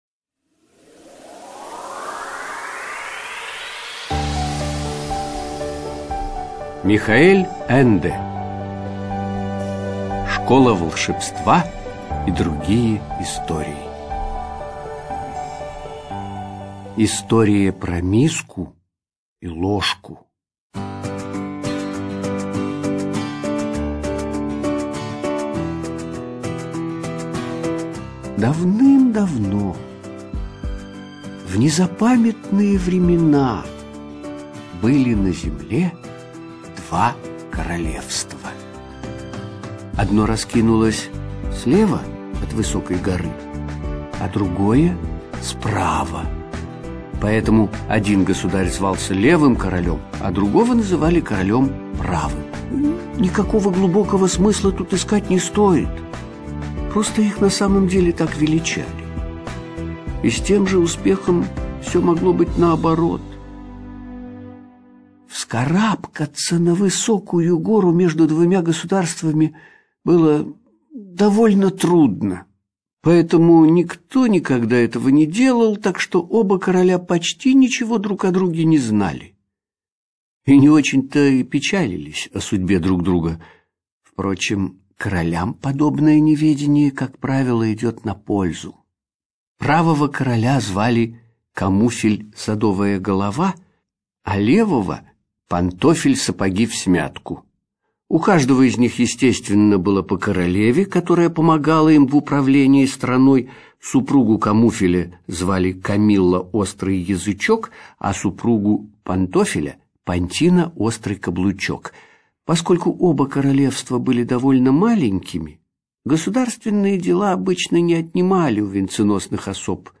Студия звукозаписиАмфора